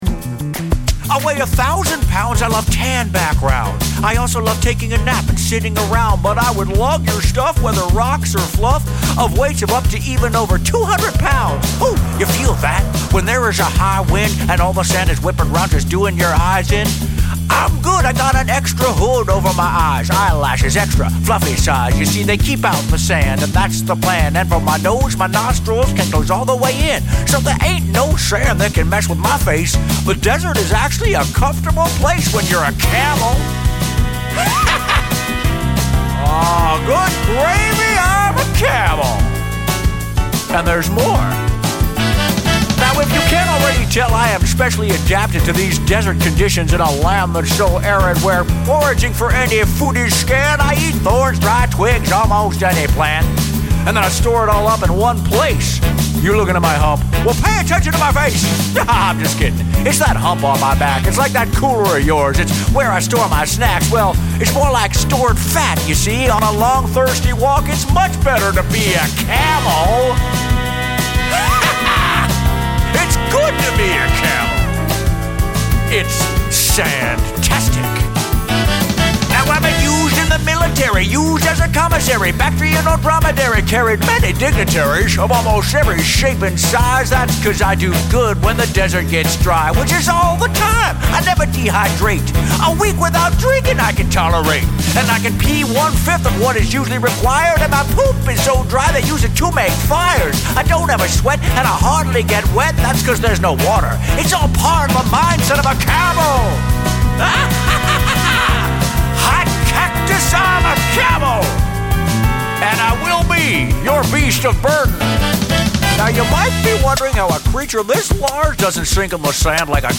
catchy original songs